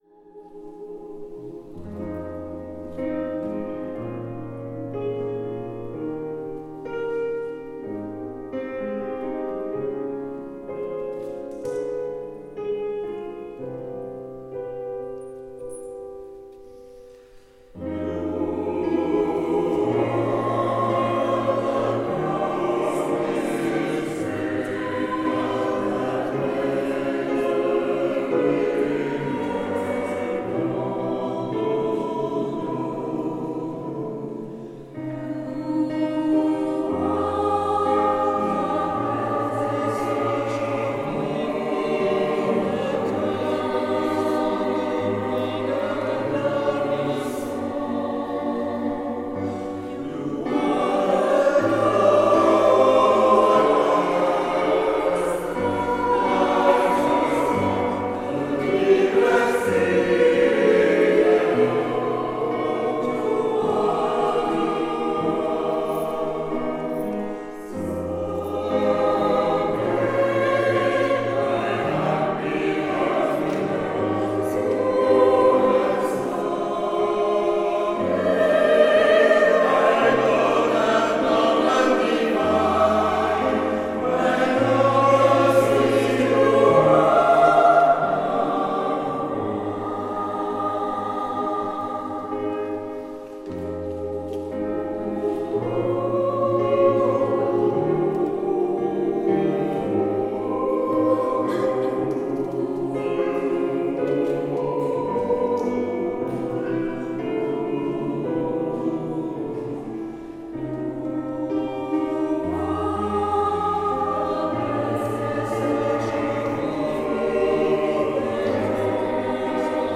Musique chorale américaine
Extraits de la restitution de fin de stage dans l'église de Sainte Thumette à Penmarc'h, le 3 mai 2024